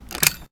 weapon_foley_drop_02.wav